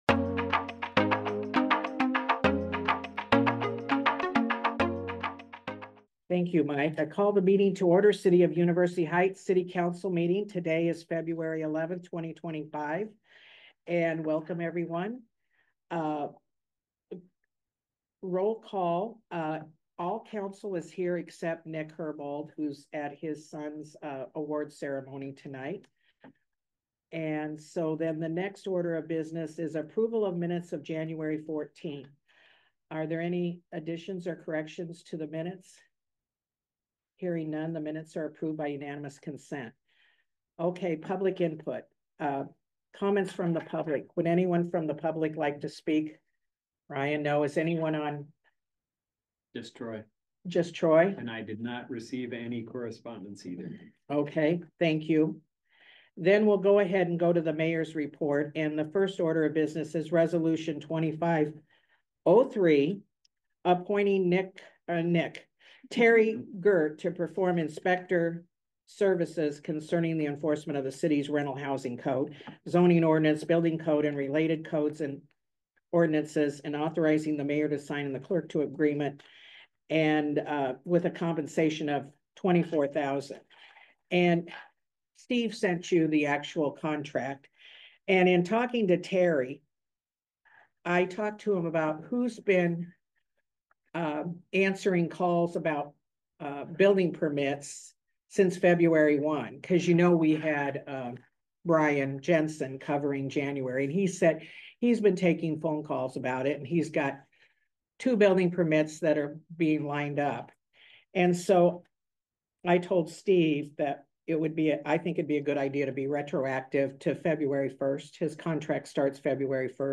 University Heights City Council Meeting of 02/11/25